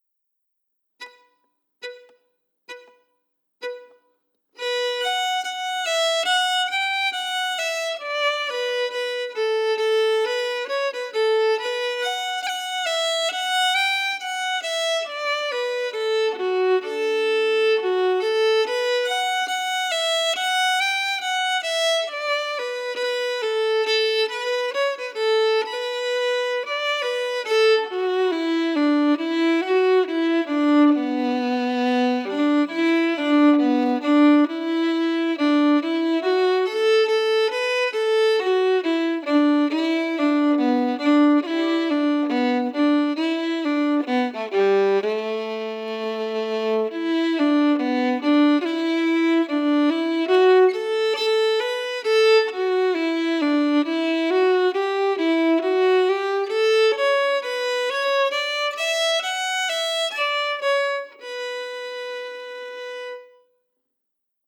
Key: Bm
Form: Reel
Played slowly for learning